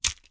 Close Combat Break Bone 9.wav